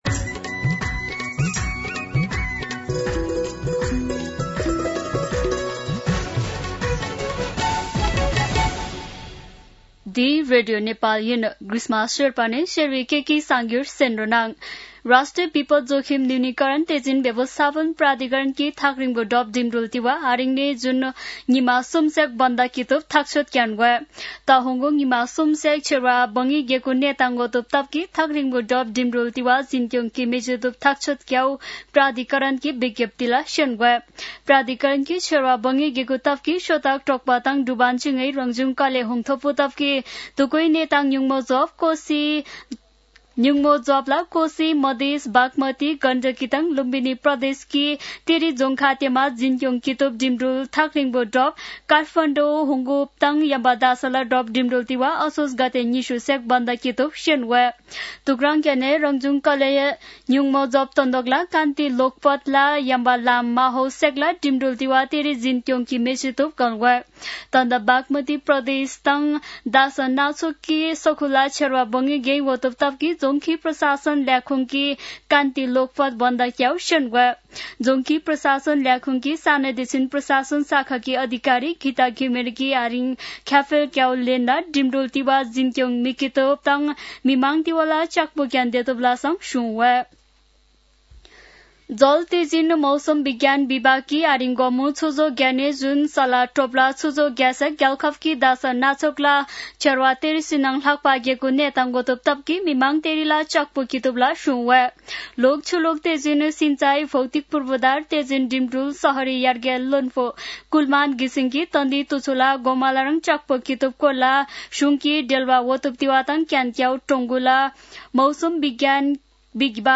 शेर्पा भाषाको समाचार : १८ असोज , २०८२